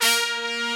DreChron Brass Synth.WAV